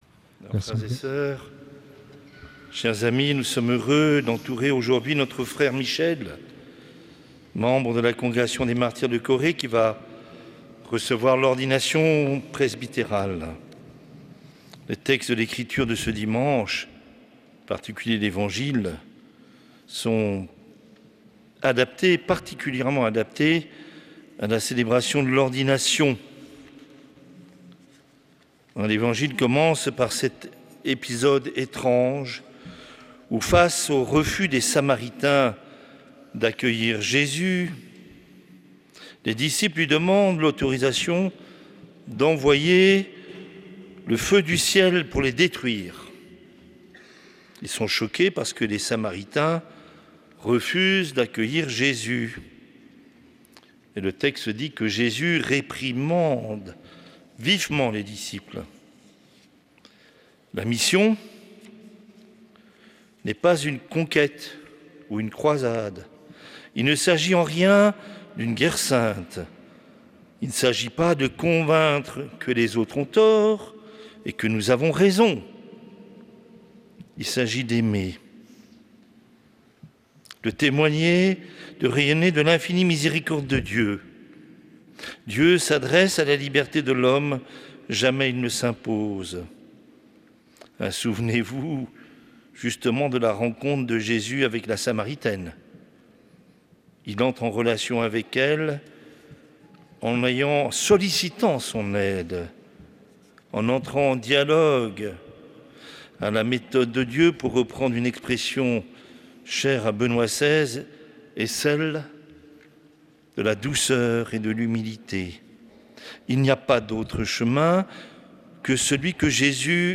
Homélie de Mgr Yves Le Saux